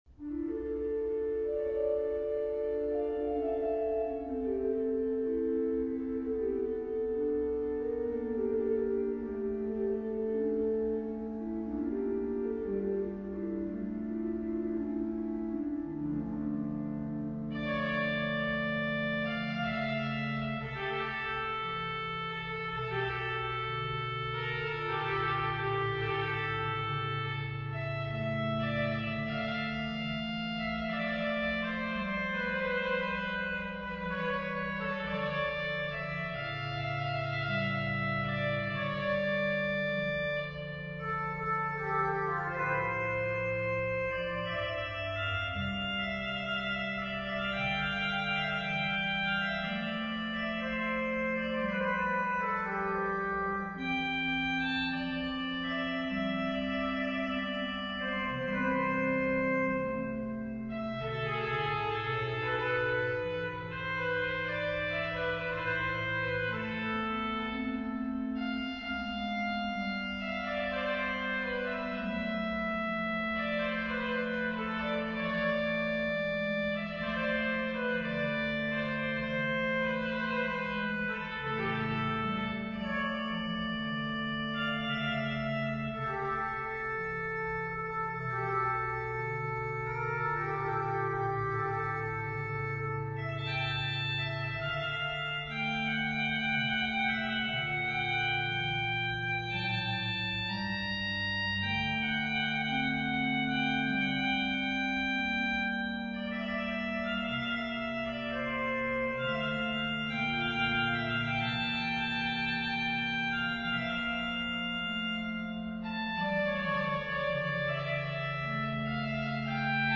Central-Church-5-10-20-worship-CD.mp3